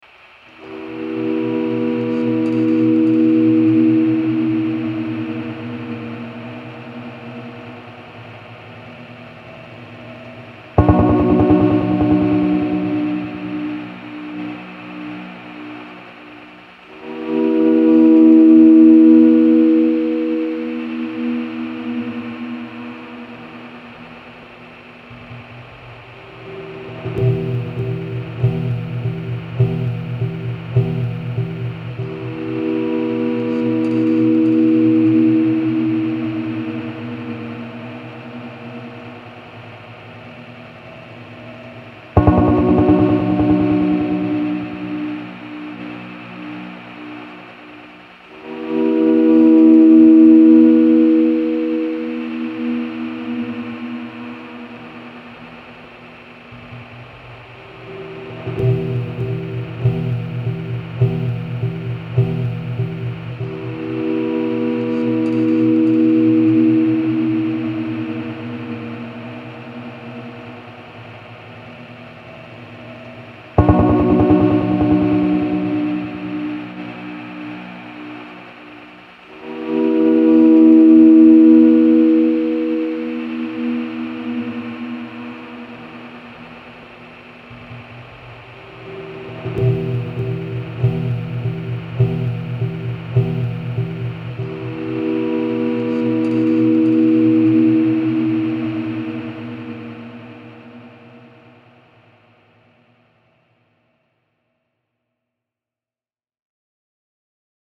Disorientating atmospheric tones.